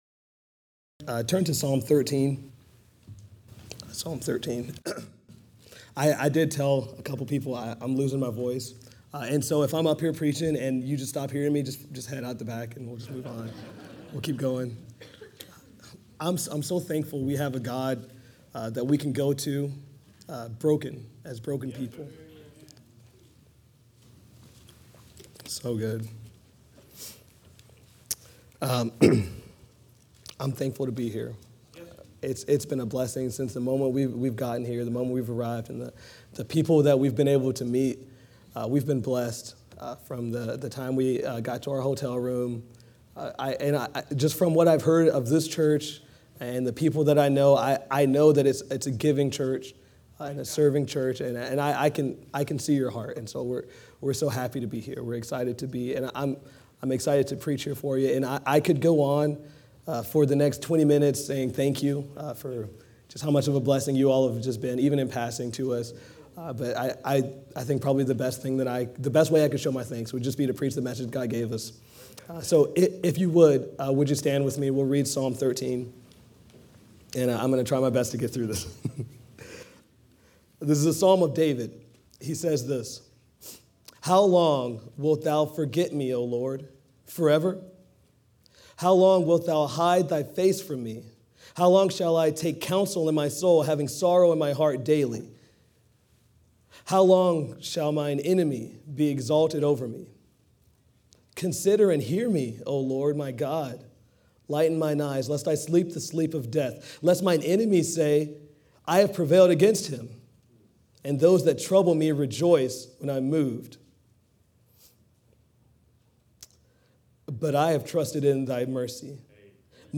A message from the series "The Armor of God."